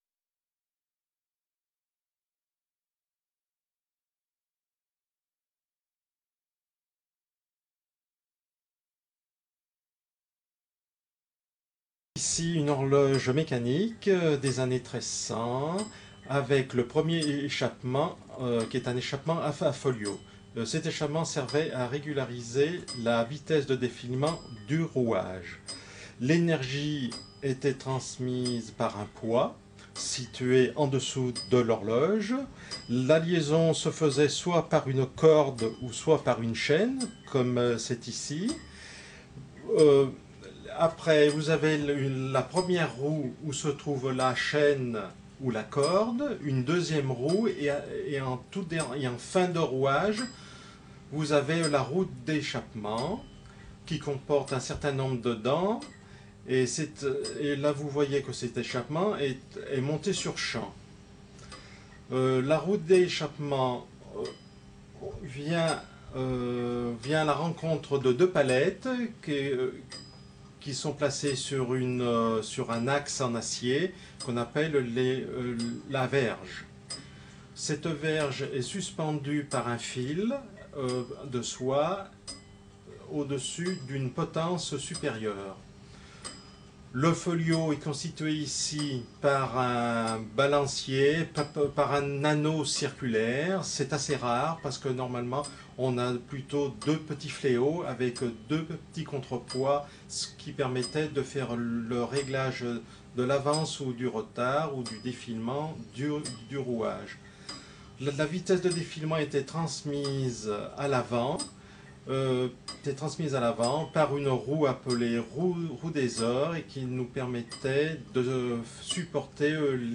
Horloge à foliot du XVe siècle (19 Mo) — Association Européenne pour l'Éducation en Astronomie